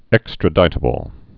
(ĕkstrə-dītə-bəl)